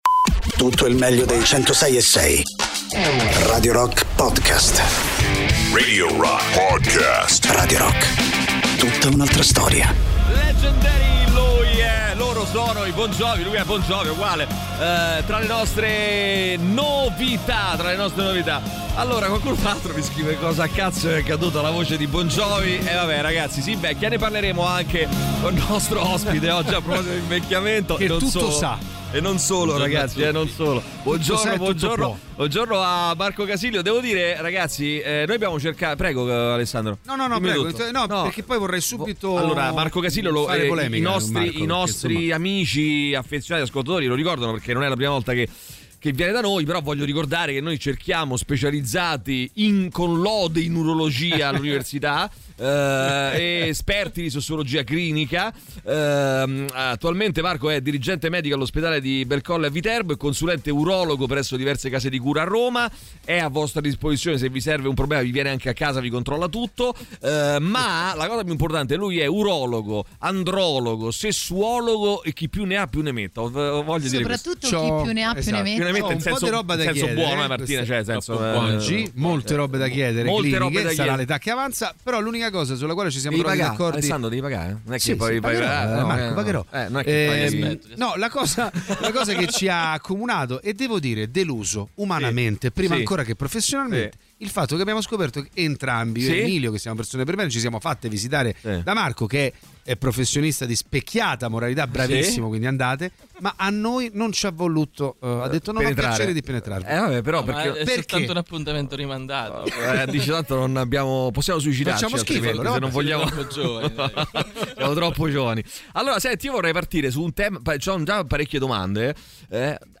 Radio Rock FM 106.6 Interviste